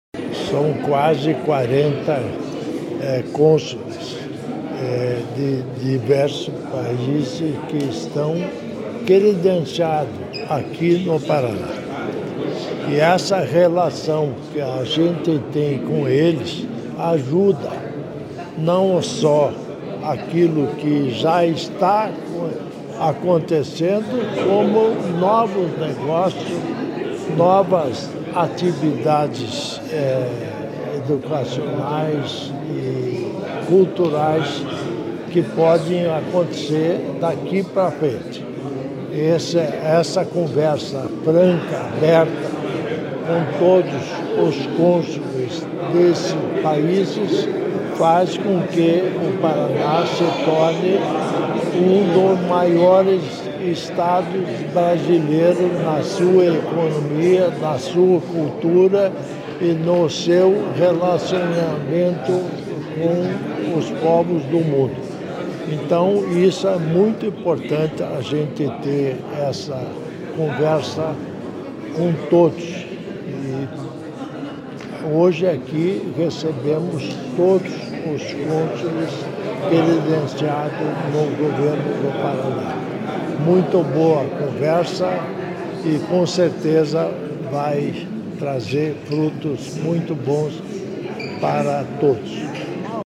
Sonora do governador em exercício Darci Piana sobre reunião com Corpo Consular